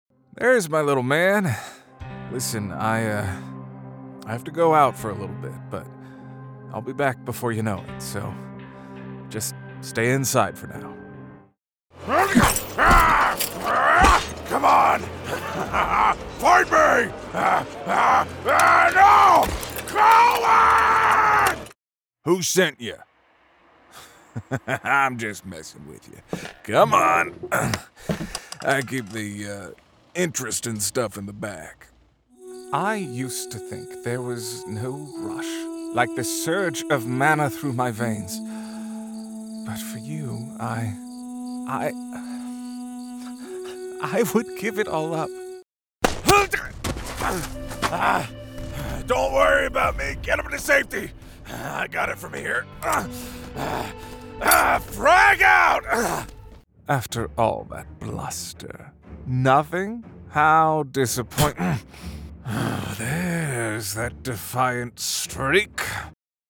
Young Adult
Middle Aged
He has also set up a broadcast quality home studio, and is proficient in audio editing.